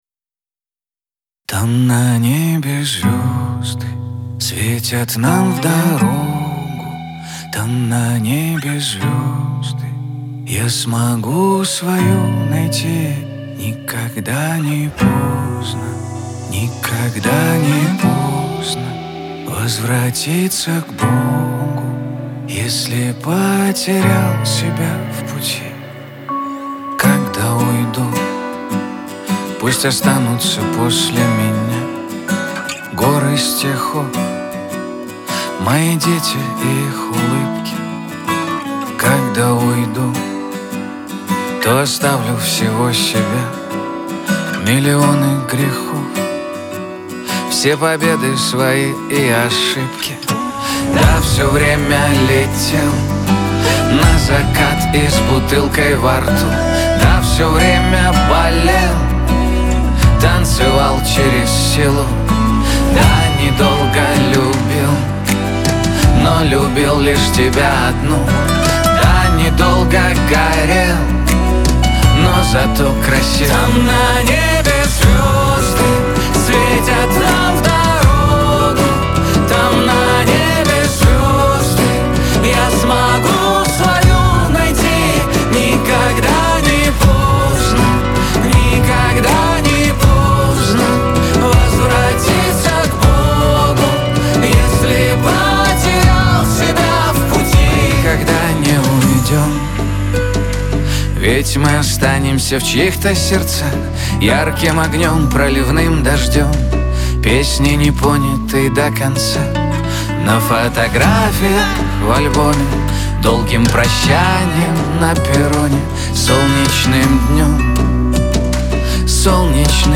ХАУС-РЭП , эстрада , pop